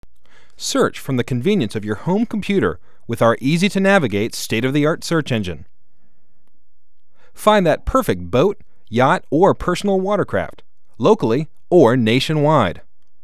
Boat Shopper Online VO -
boat_trader_vo_1.wav